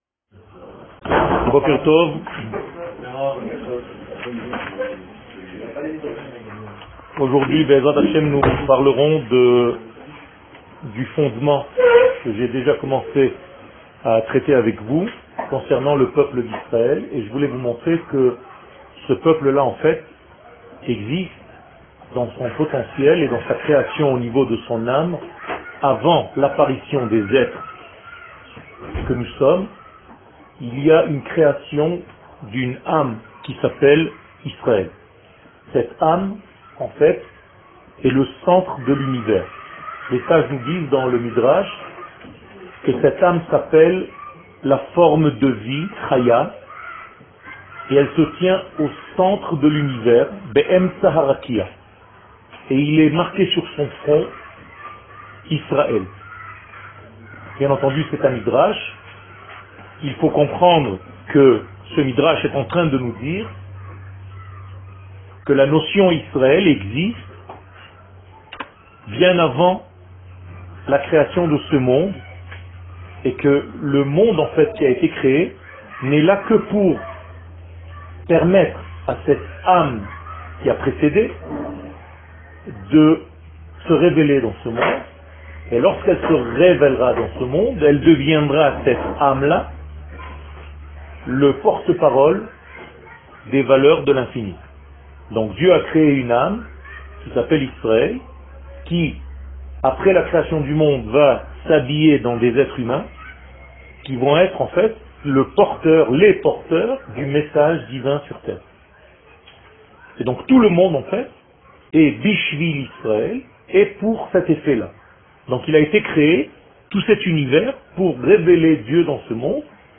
Eretz Israel שיעור מ 21 נובמבר 2017 52MIN הורדה בקובץ אודיו MP3 (8.97 Mo) הורדה בקובץ אודיו M4A (6.22 Mo) TAGS : Torah et identite d'Israel שיעורים קצרים